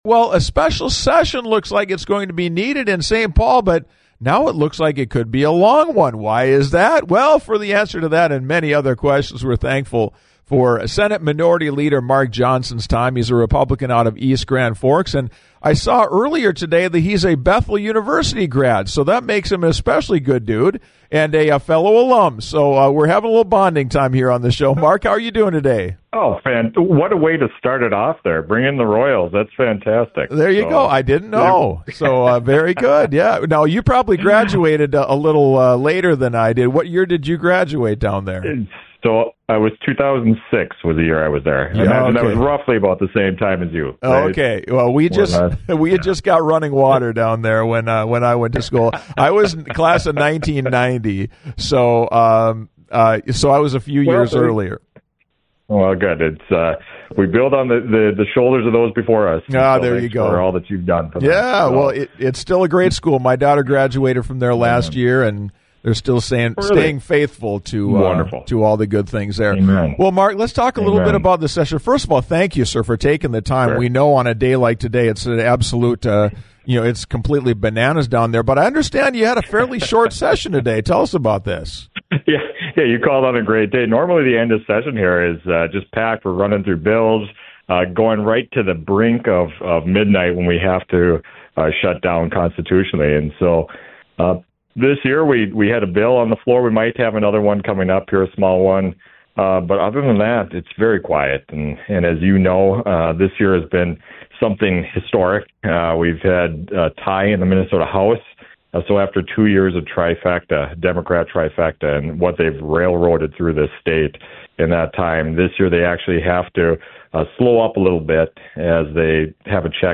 Minnesota Legislature headed for a special session